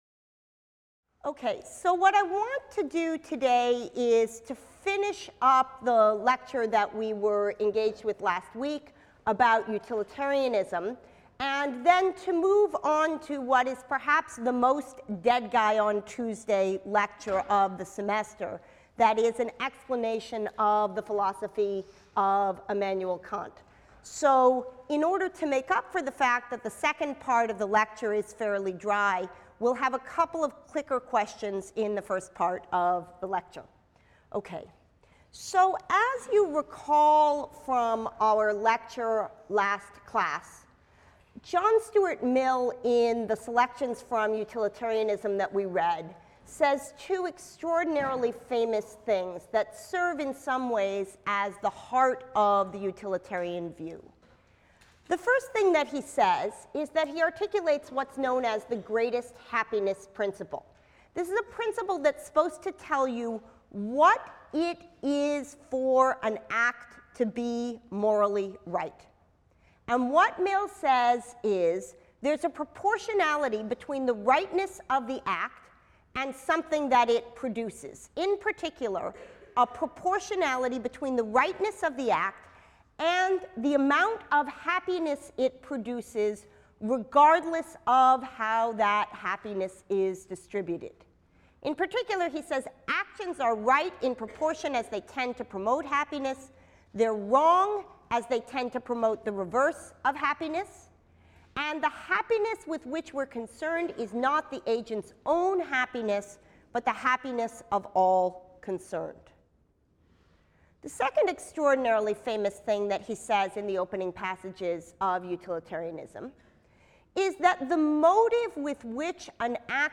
PHIL 181 - Lecture 13 - Deontology | Open Yale Courses